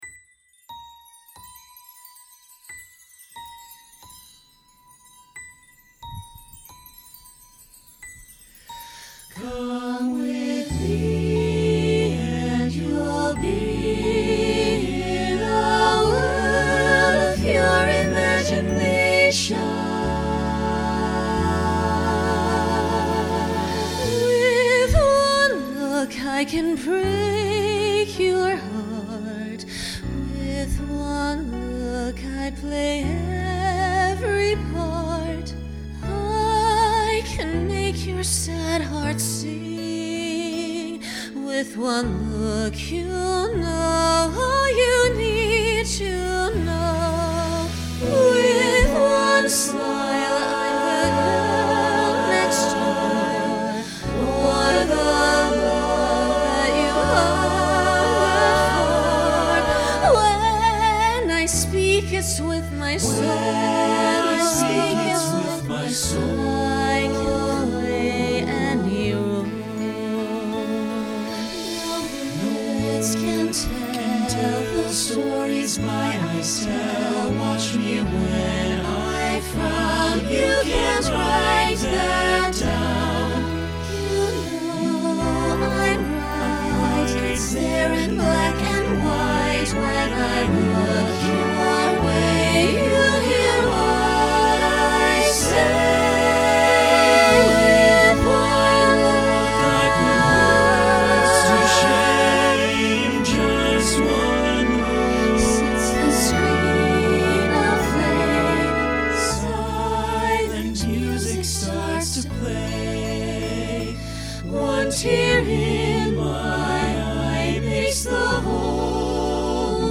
Genre Broadway/Film
Function Ballad Voicing SATB